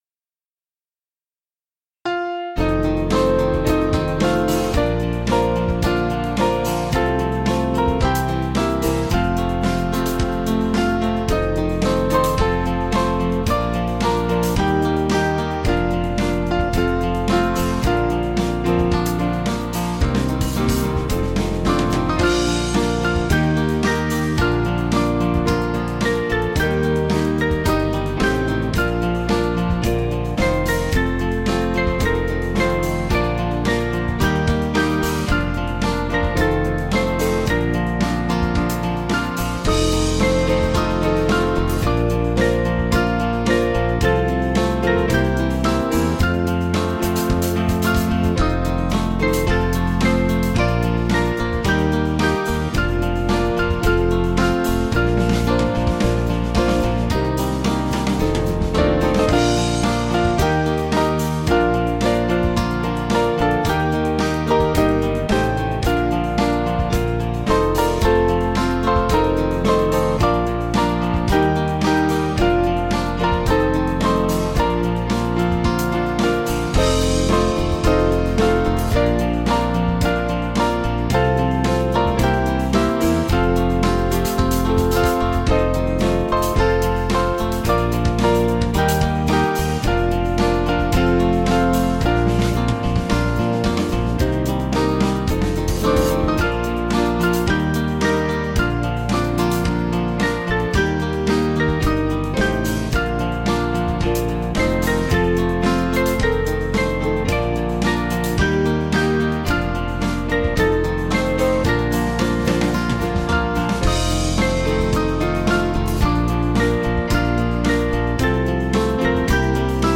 Small Band
(CM)   4/Bb 487.3kb